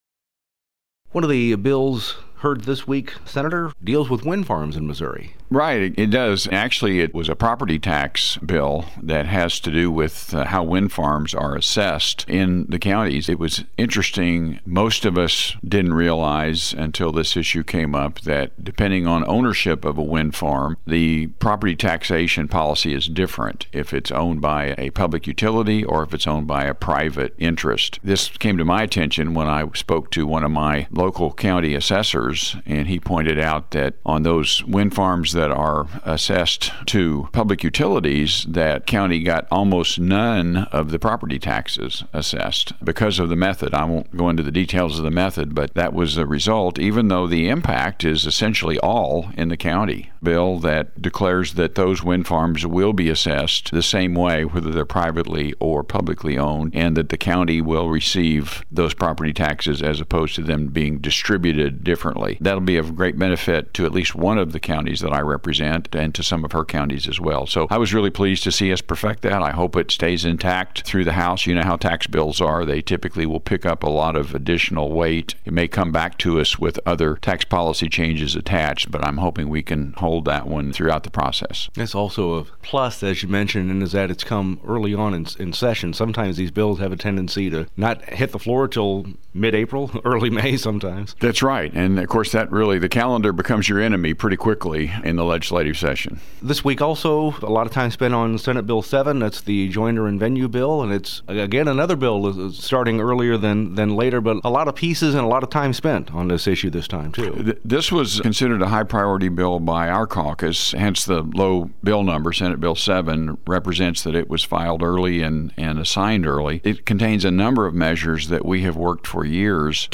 JEFFERSON CITY — State Sen. Ed Emery, R-Lamar, discusses Senate Bill 72, legislation that seeks to define wind farms as local property for property tax assessments of electric companies.